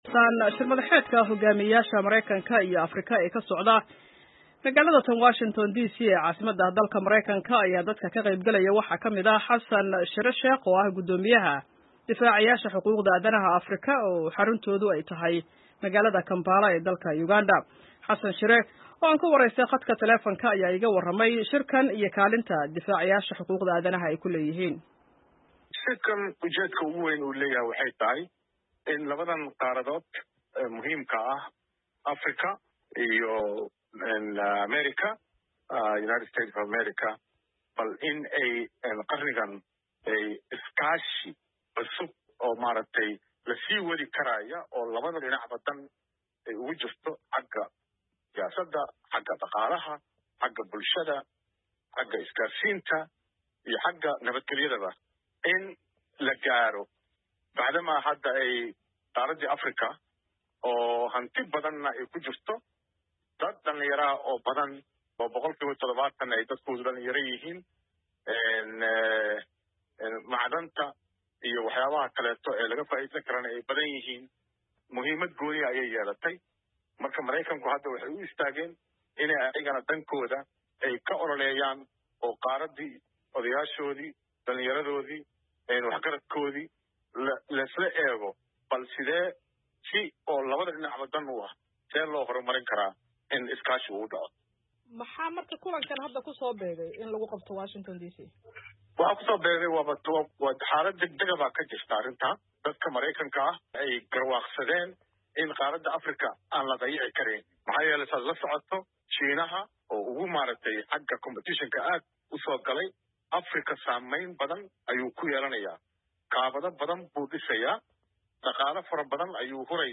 Wareysi: Kaalinta bulshada rayidka ah ee shir-madaxeedka Mareykanka iyo Afrika